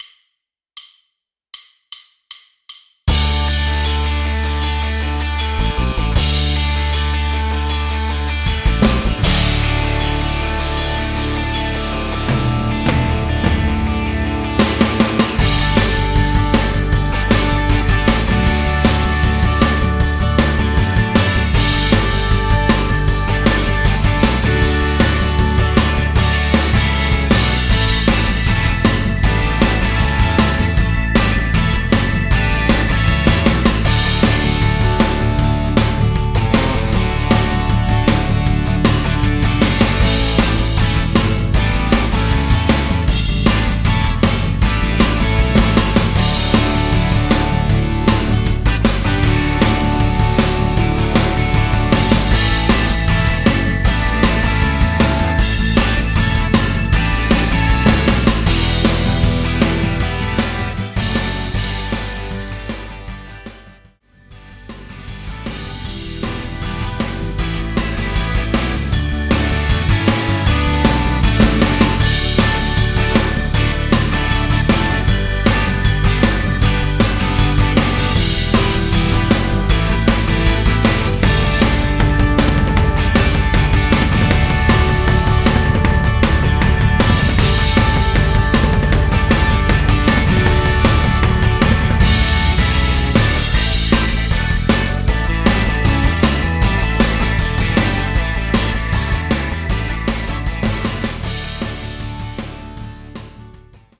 Midifile